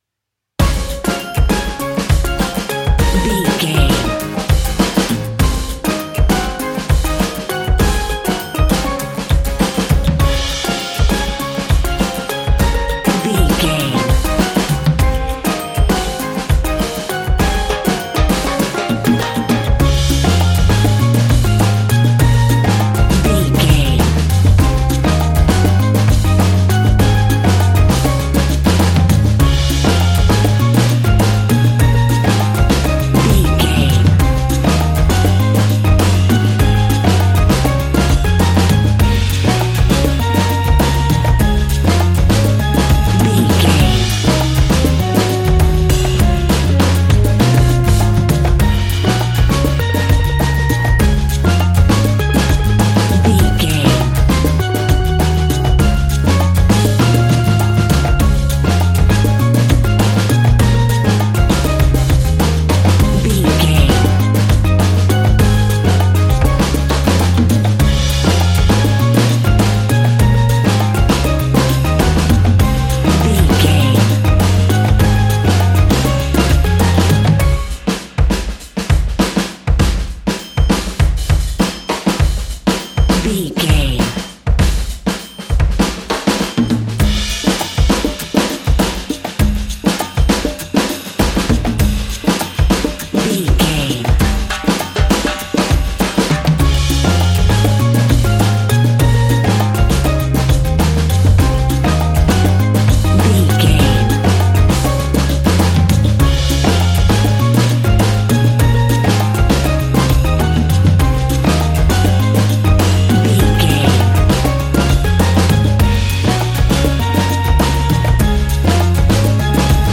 Ionian/Major
D♭
calypso
steelpan
happy
drums
percussion
bass
brass
horns
guitar